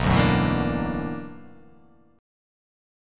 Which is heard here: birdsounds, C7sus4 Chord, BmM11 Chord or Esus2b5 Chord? BmM11 Chord